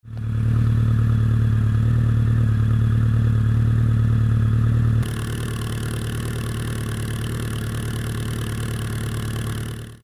アイドリング音量の比較（4秒後にバッフル付きに変わります）
1メートル離れた所から収録していますが
4dBほど音量が下がっています｡
ほとんどが大口径による低音なので